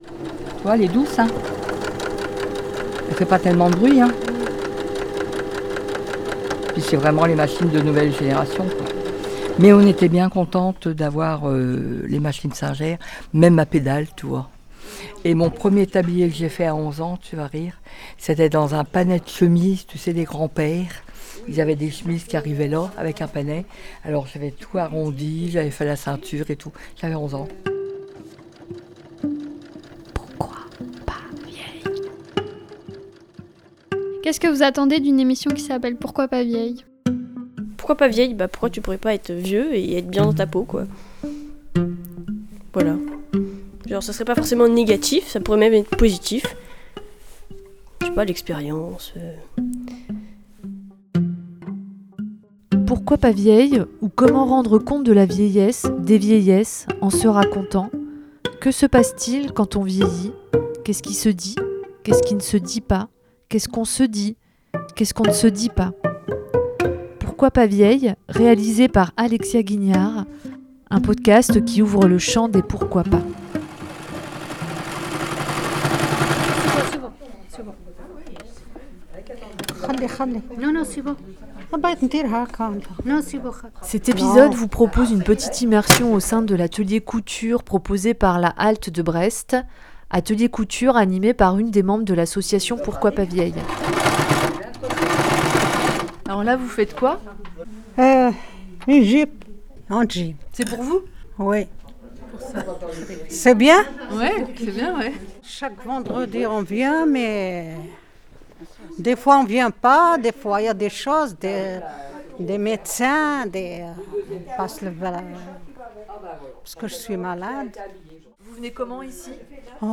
Histoires de fil à La Halte accueil de Brest